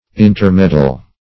Intermeddle \In`ter*med"dle\, v. t.